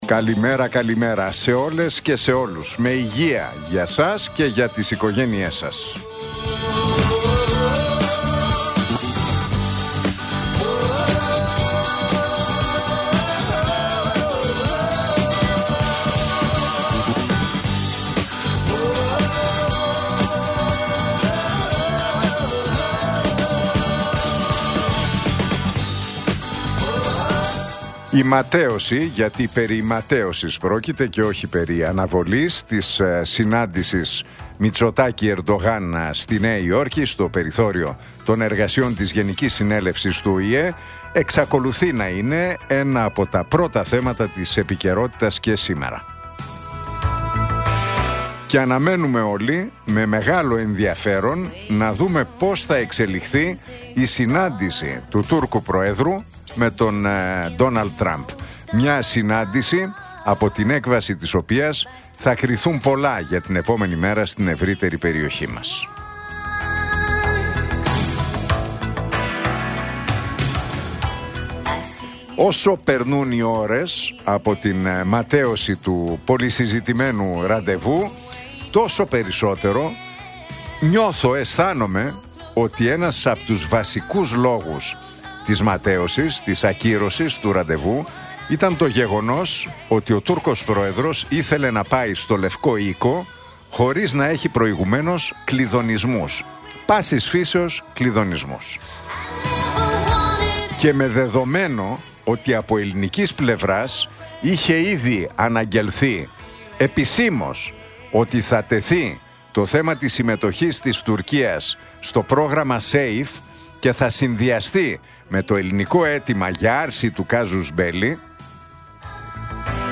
Ακούστε το σχόλιο του Νίκου Χατζηνικολάου στον ραδιοφωνικό σταθμό Realfm 97,8, την Πέμπτη 25 Σεπτεμβρίου 2025.